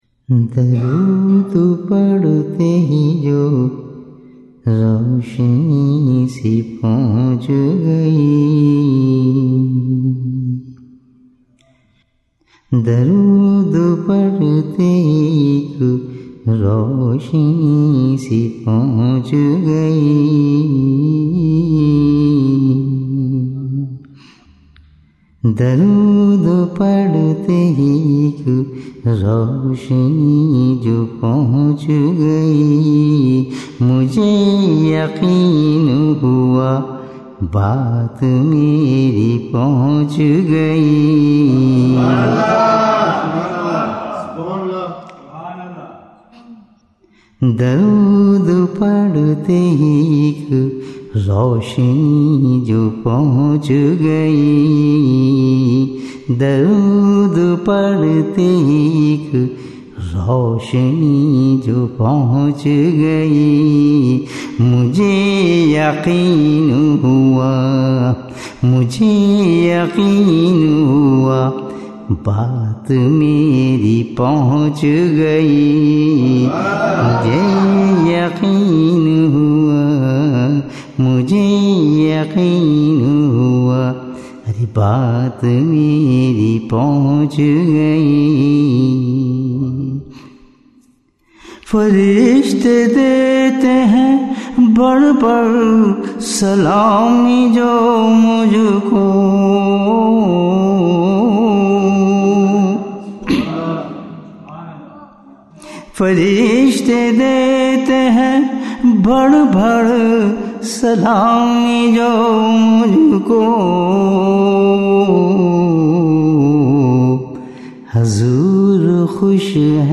اجتماع 2024 نعت شریفIjtema 2024 Naat Shareef (11 minutes)